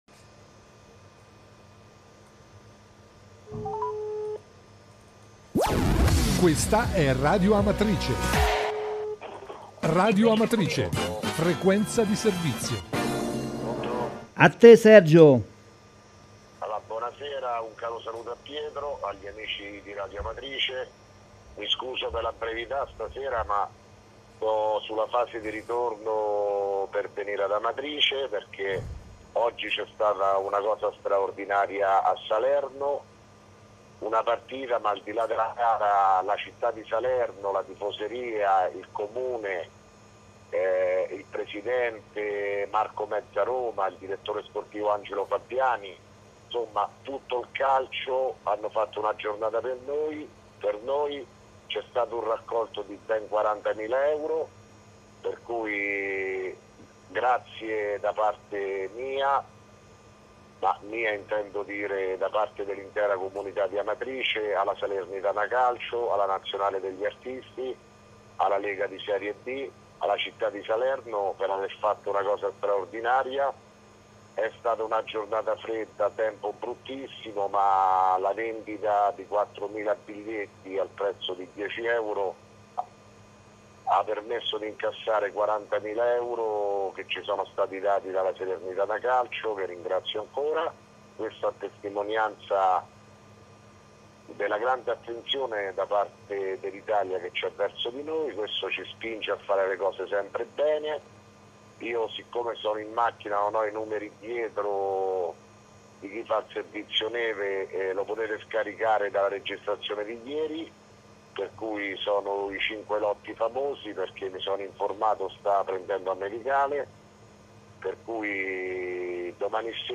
RADIO AMATRICE: COMUNICATO AUDIO DEL SINDACO PIROZZI (15 GEN 2017) - Amatrice
Di seguito il messaggio audio del Sindaco Sergio Pirozzi, del 15 gennaio 2017.